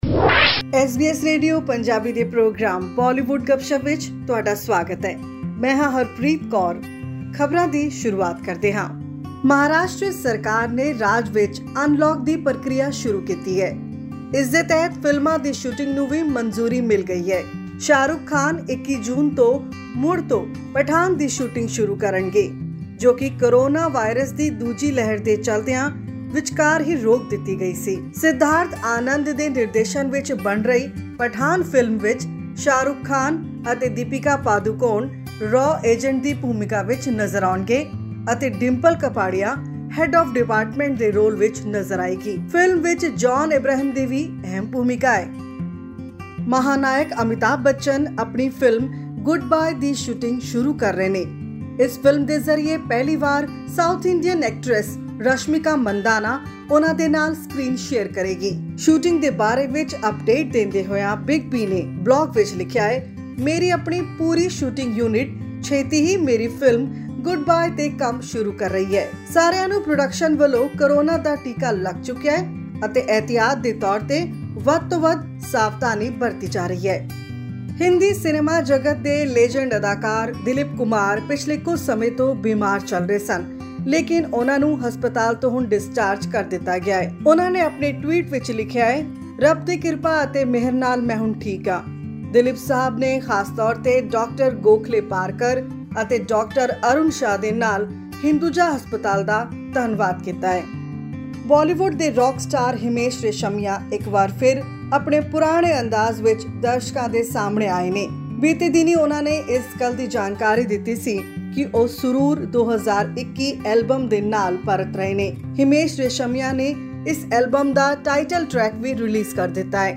Many big wigs of Bollywood have jumped in to resume the production of their films that were stalled due to the second wave of COVID-19 In India. All this and more in our weekly segment from the world of cinema and music.